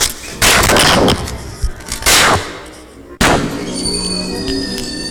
Slaves 1_2 74bpm.wav